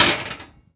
metal_impact_light_03.wav